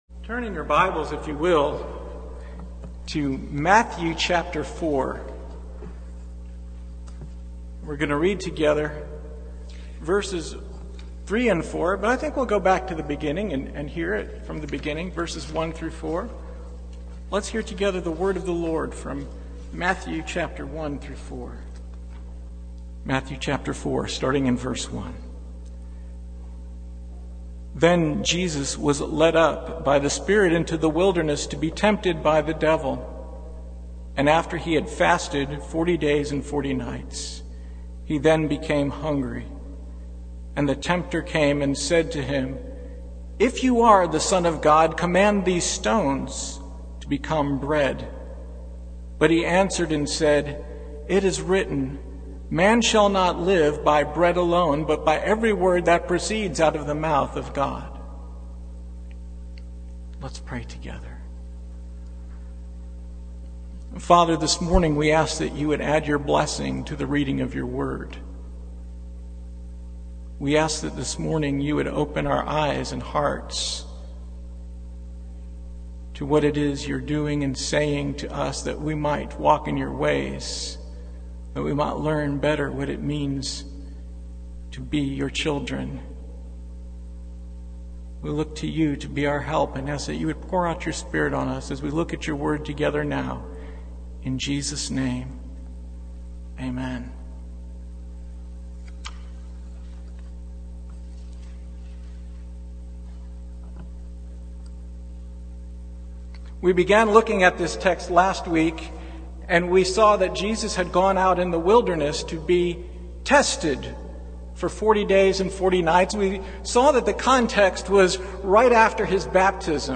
Passage: Matthew 4:3-4 Service Type: Sunday Morning